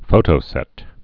(fōtō-sĕt)